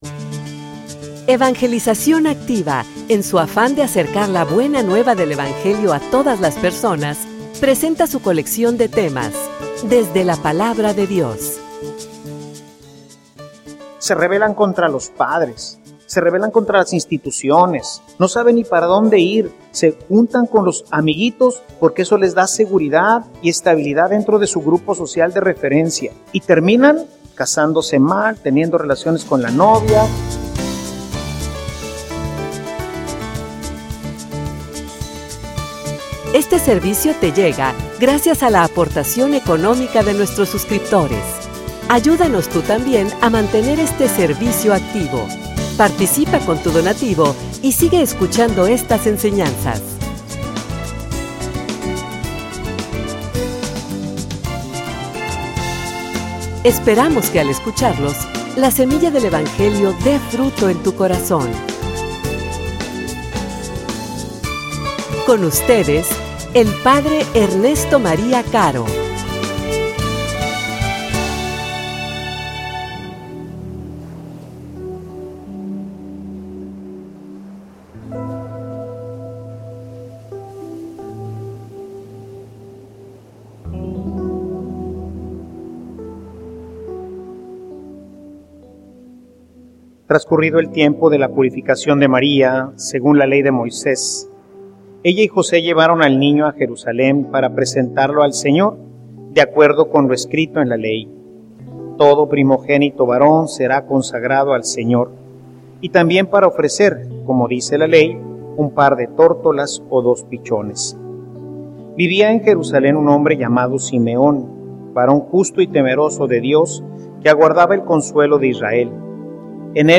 homilia_Formacion_que_tiene_consecuencias.mp3